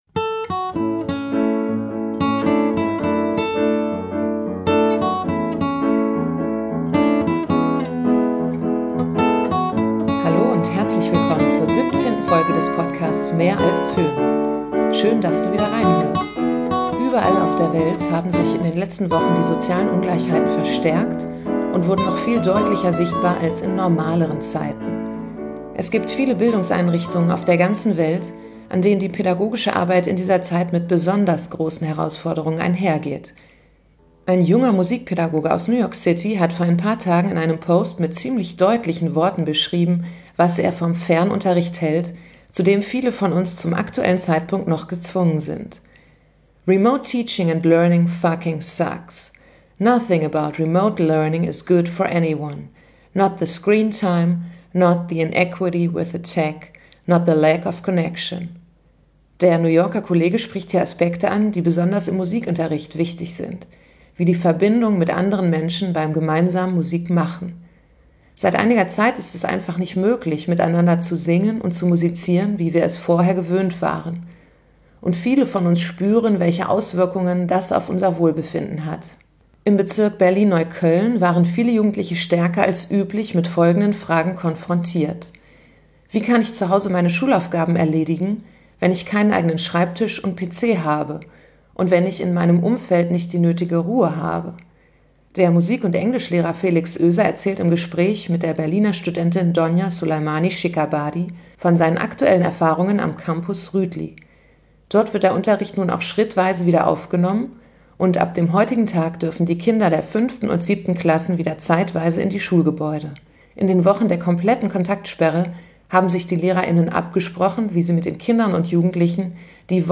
im Gespräch